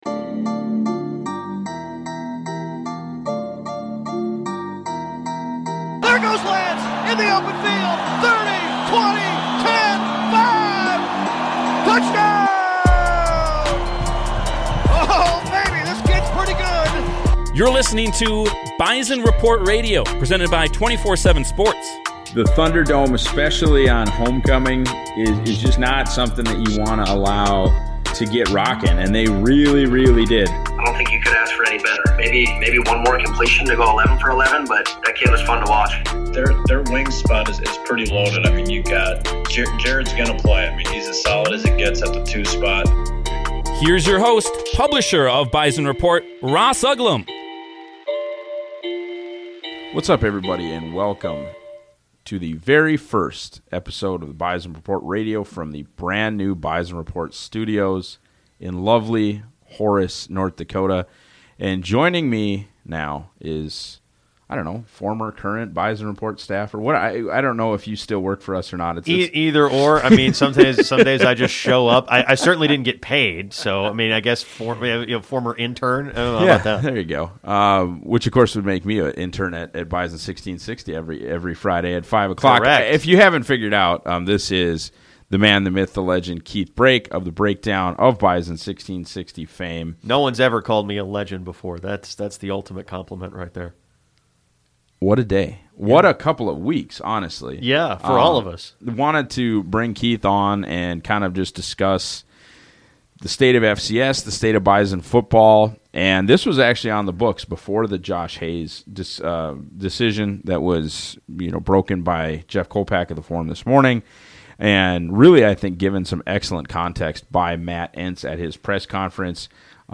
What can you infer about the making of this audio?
IN STUDIO!! christen the brand new Bison Report Studio in Horace North Dakota to take on the day's biggest NDSU stories.